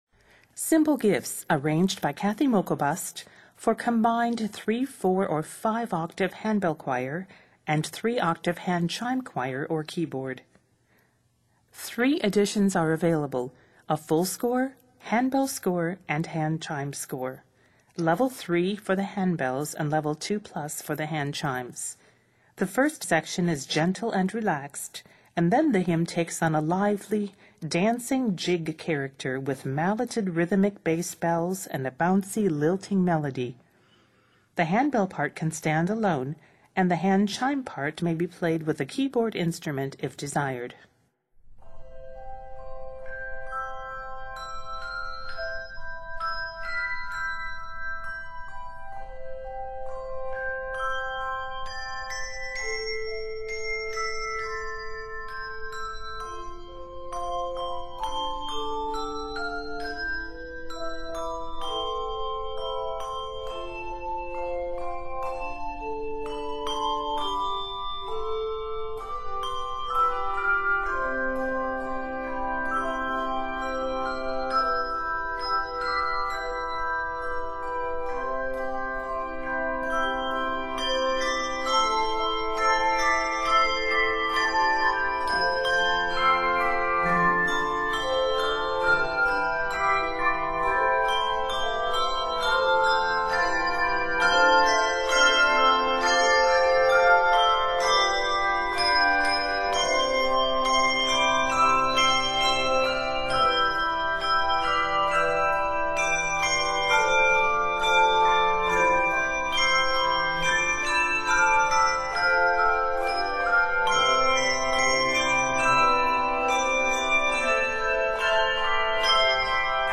Set in C Major and F Major, measures total 132.